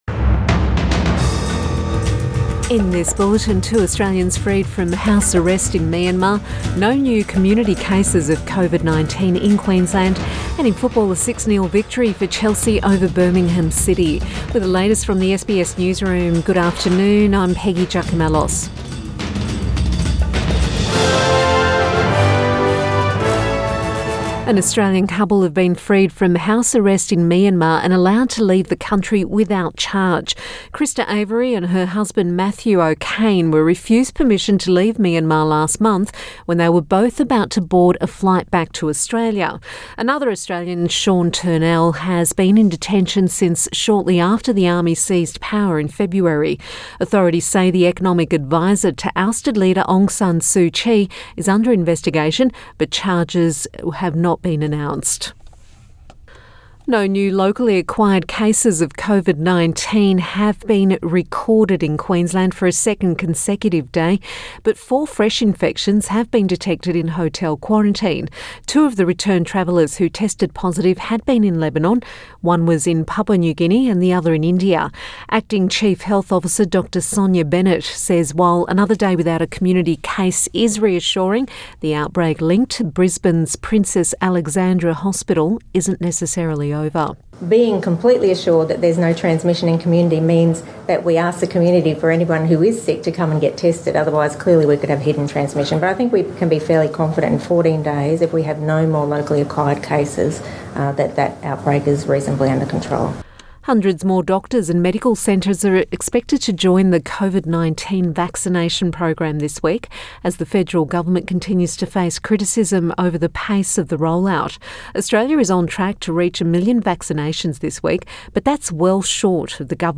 Midday bulletin 5 April 2021